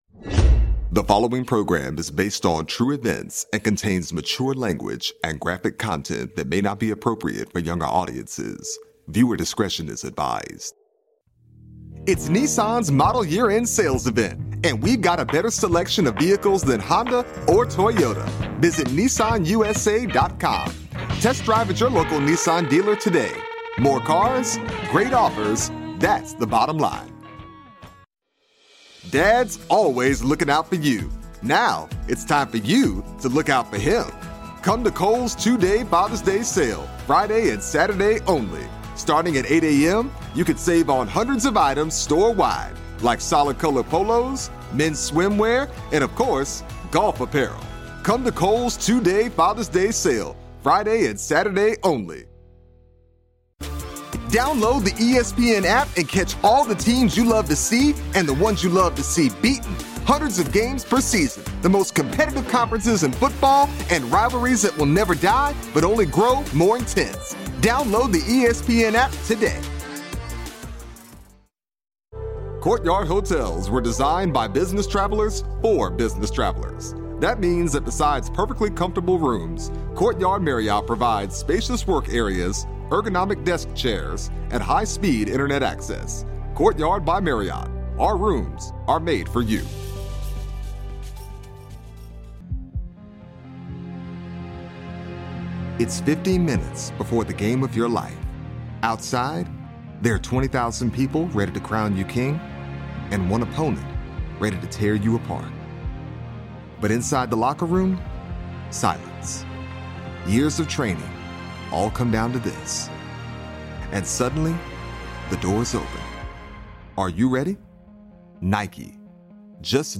Englisch (US)
Komisch
Autorisierend
Unverwechselbar